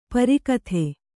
♪ pari kathe